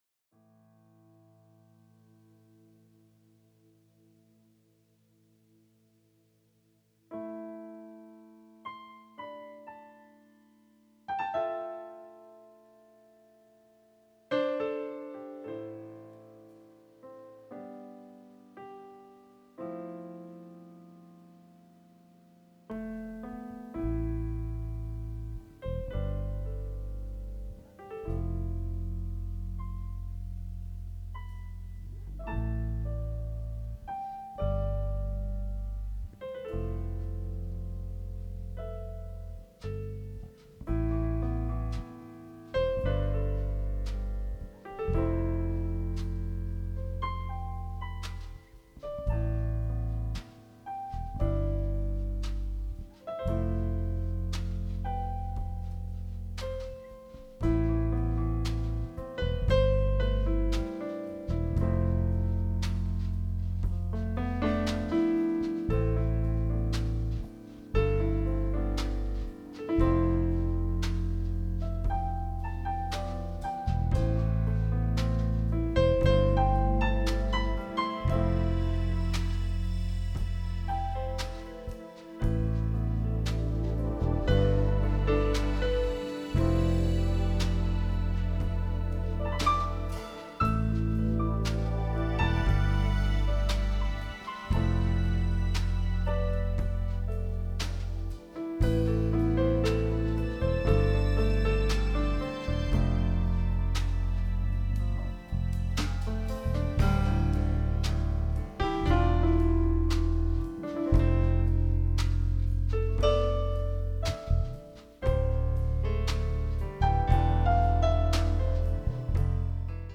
Experimental instrumental music
sax, keyboards